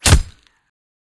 knife_hitwall2.wav